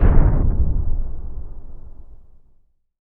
LC IMP SLAM 2C.WAV